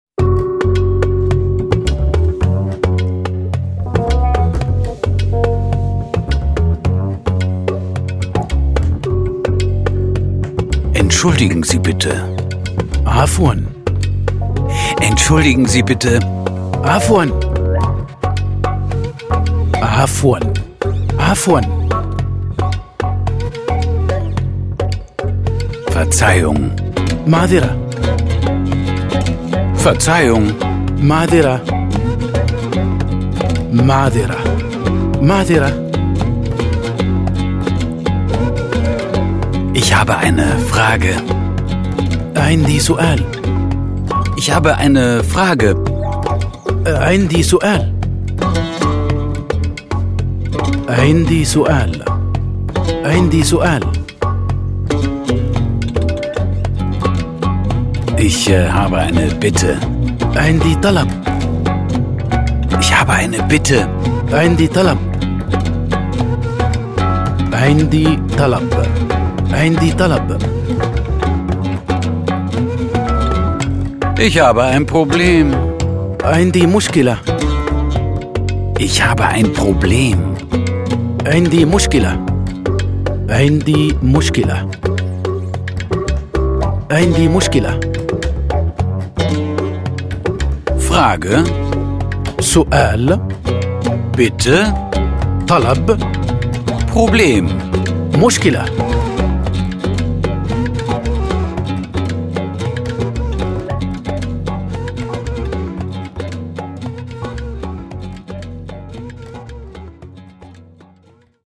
Groovy Basics.Coole Pop & Jazz Grooves / Audio-CD mit Booklet
Tauchen Sie mit orientalischen Klängen in die arabische Sprache und Kultur ein: Die neuen Groovy Basics sind der ideale Einstieg, um im Urlaub oder auf Geschäftsreisen auf Arabisch zu kommunizieren.
Erfahrene Sprachenspezialisten, Profisprecher und internationale Spitzenmusiker begleiten durch diesen unterhaltsamen Arabisch-Sprachkurs.
Mit den coolen Pop & Jazz Grooves entspannt die wichtigsten Vokabeln, Redewendungen sowie smarte Sprüche für Anfänger lernen.